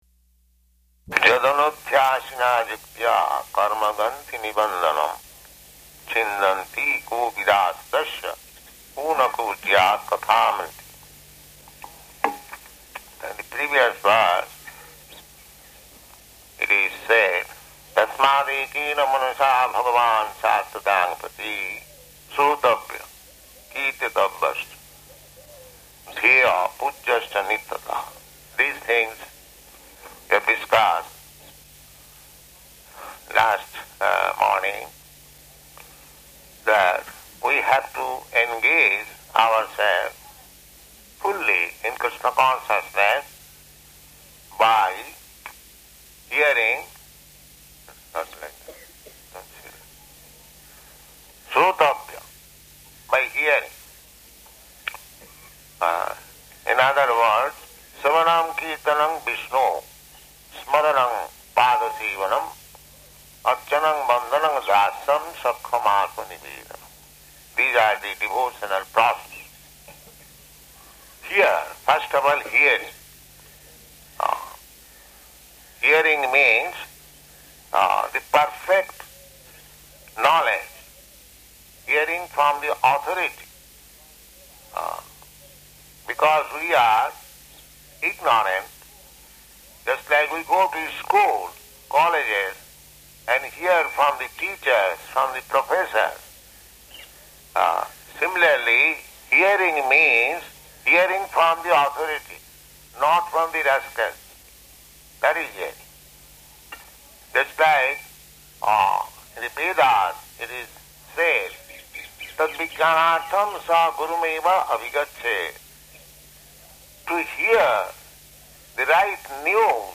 Type: Srimad-Bhagavatam
Location: Vṛndāvana